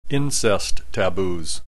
click this icon to hear the preceding term pronounced